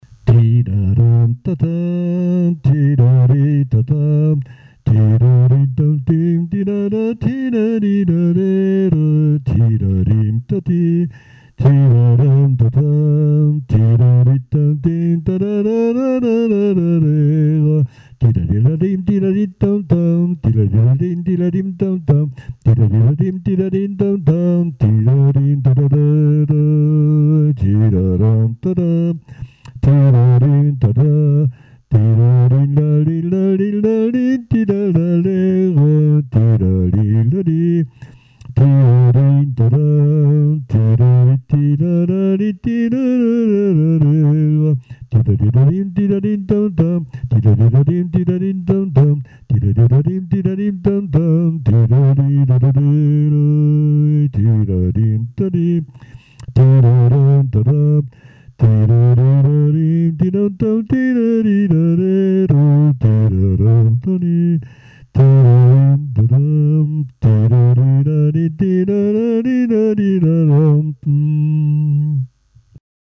Saint-Prouant
danse : quadrille : avant-quatre
circonstance : fiançaille, noce
Pièce musicale inédite